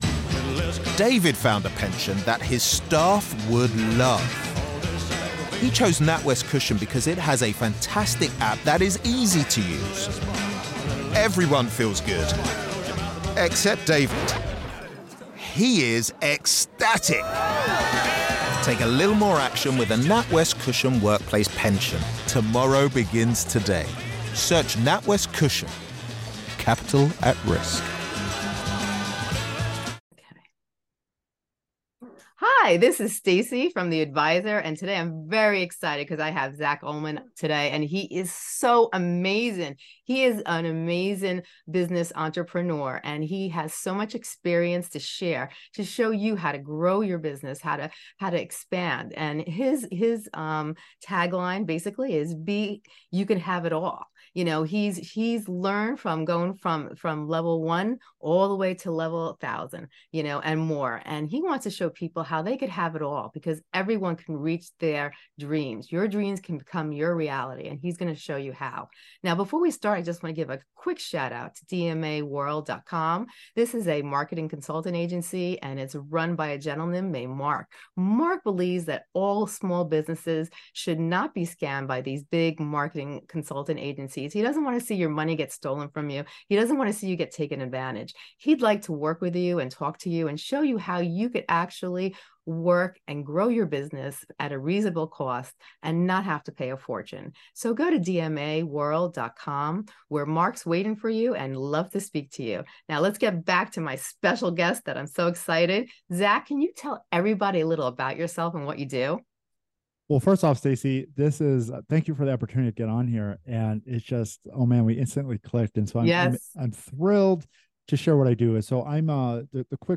In this captivating episode, we welcome special guest